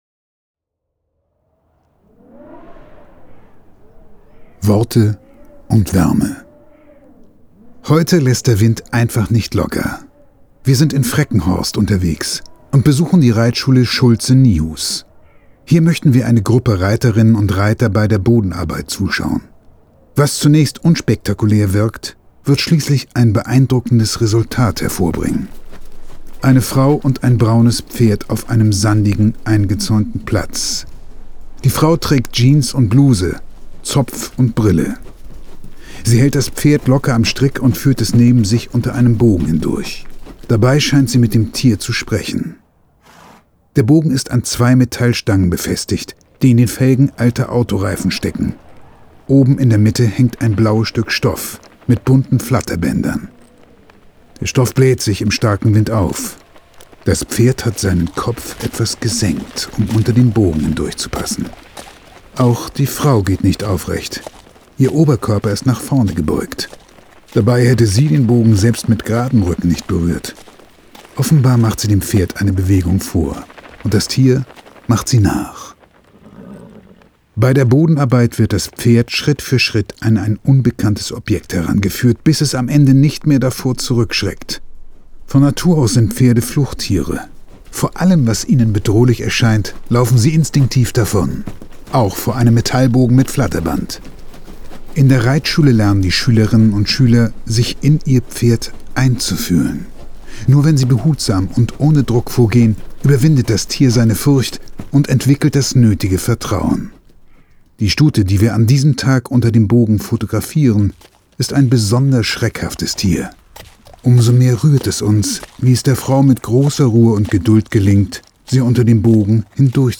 Unser Hörbuch genießen Sie am besten mit Kopfhörern .
pferde2020_hoerbuch_taste_3__worteundwaerme__master.mp3